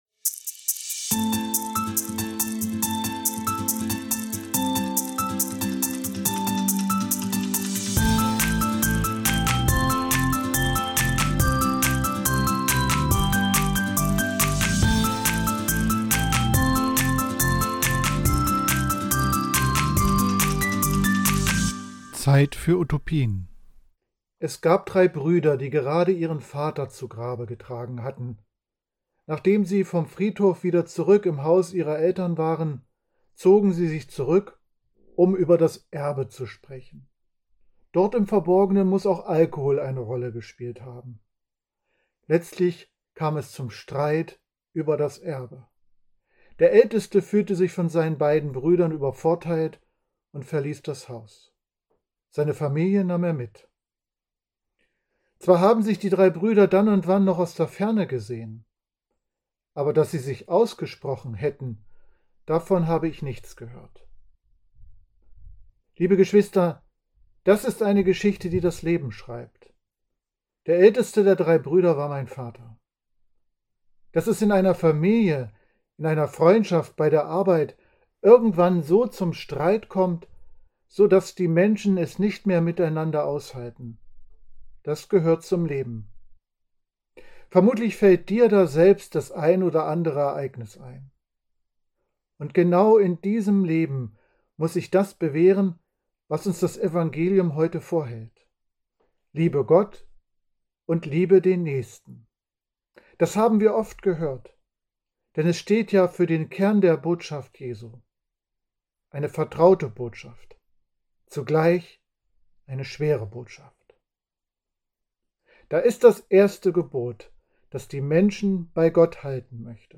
Predigt zum 31. Sonntag im Jahreskreis (B) am 3.11.2024 in der Kathedrale St. Sebastian Magdeburg